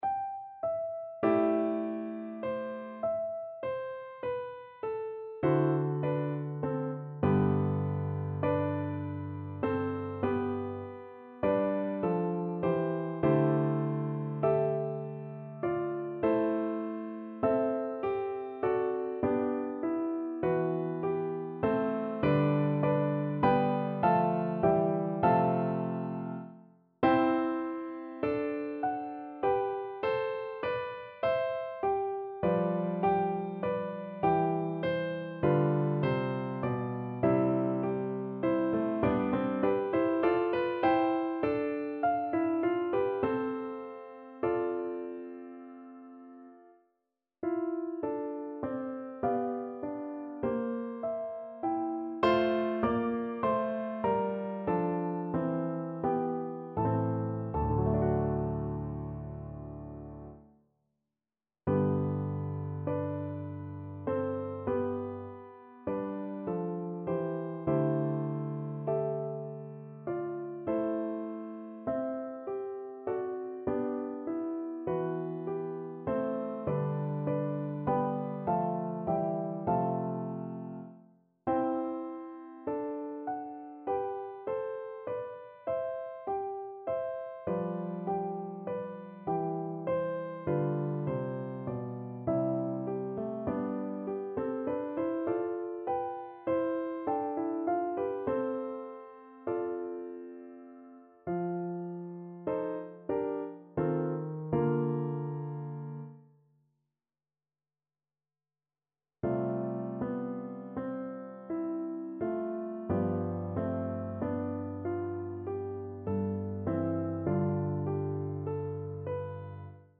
5/4 (View more 5/4 Music)
Classical (View more Classical Viola Music)